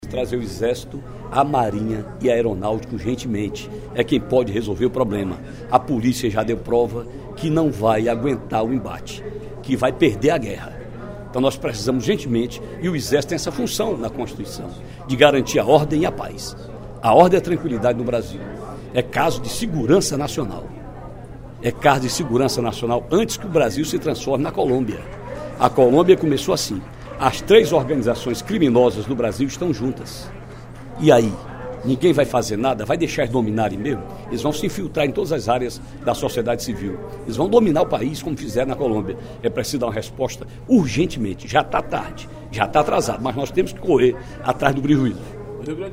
O deputado Ferreira Aragão (PDT), ao se pronunciar no primeiro expediente da sessão plenária desta terça-feira (02/08), disse que o Brasil está se transformando na Colômbia dos anos 1990, em termos de violência.